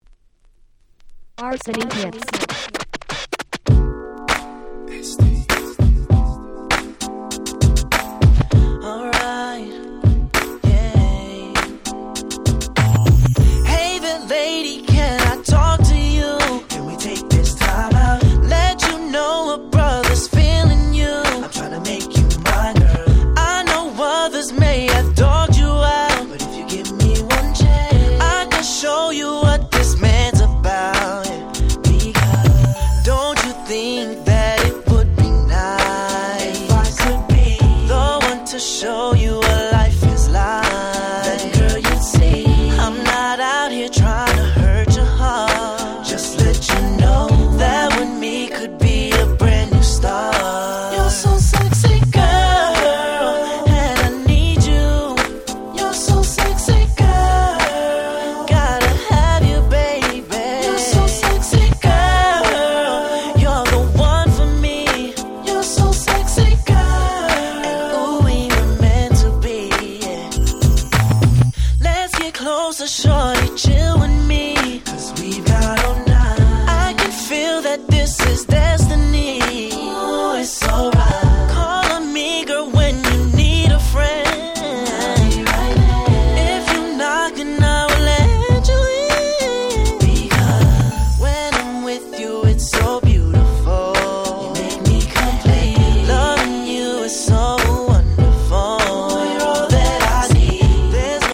07' Nice UK R&B !!
00's キラキラ系